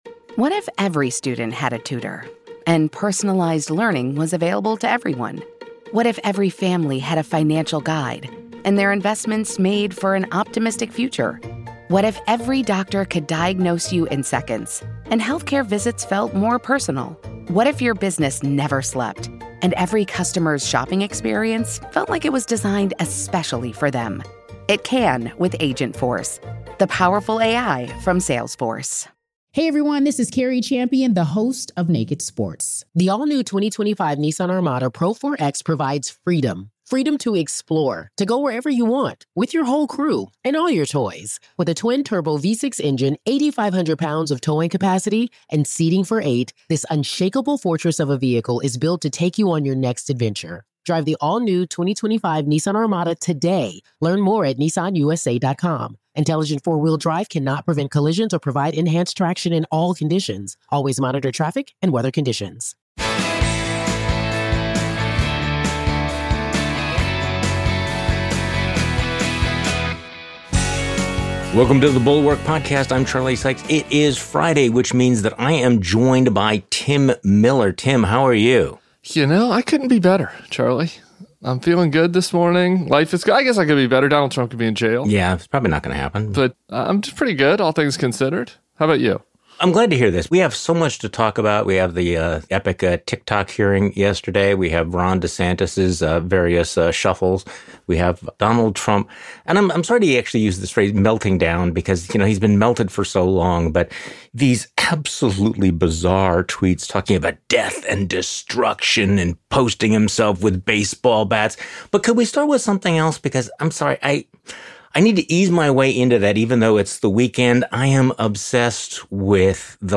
DeSantis gets over his skis on Ukraine, 'Don't Say Gay' escalates, and it's not racist to criticize the CCP. Plus, the crowd that warns of death and destruction if Trump is indicted said nothing about Trump ripping the country apart. Tim Miller joins Charlie Sykes for your weekend pod.